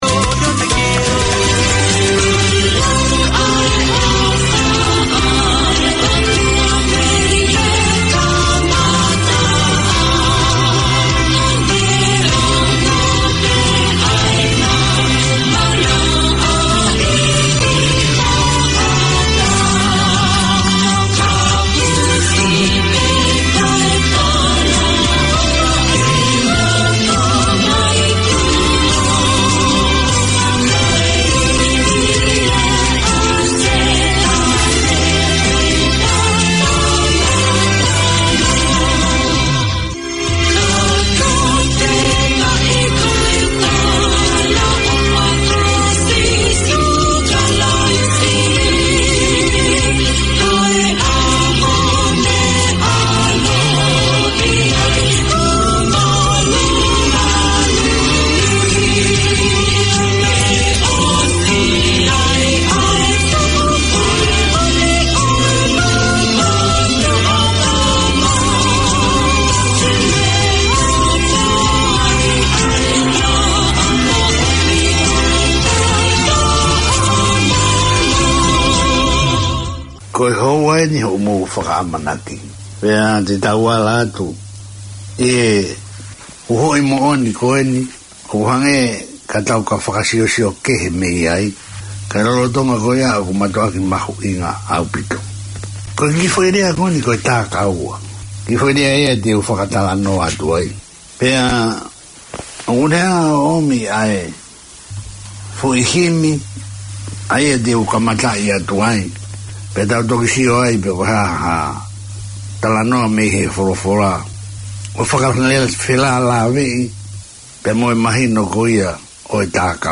The Kahoa Tauleva Trust seeks to support the well-being of the Tongan community with information on employment, money management, wealth creation, housing, physical and spiritual health. You’ll hear interviews with experts and discussions on current and topical issues and get a chance to talkback with the hosts who’ll also share devotional time with listeners.